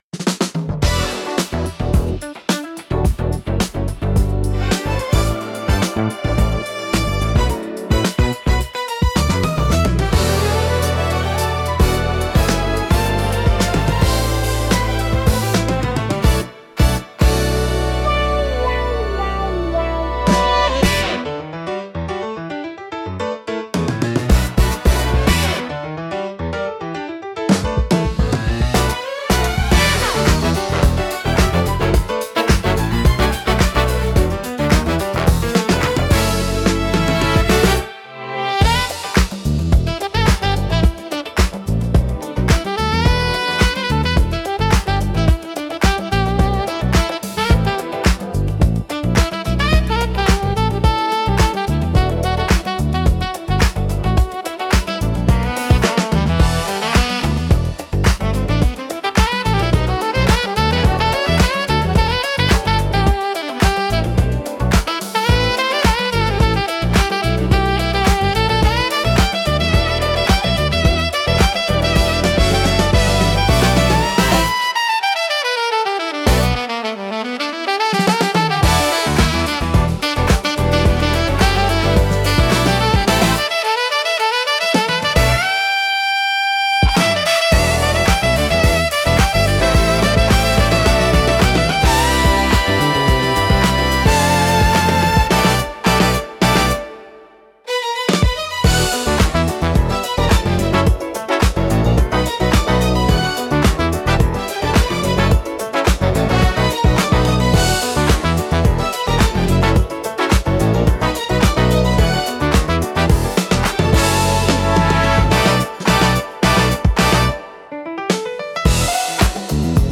エレガントでリズミカルな演奏が心地よく、ダンスフロアからカフェシーンまで幅広く親しまれています。
落ち着きつつも躍動感があり、聴く人の気分を盛り上げつつリラックスさせる効果があります。